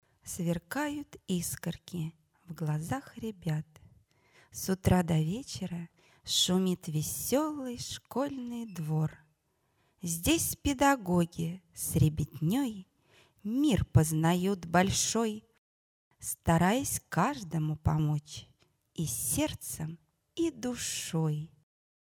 Аудиозапись визитки-представления (№1)на конкурсе "Учитель года".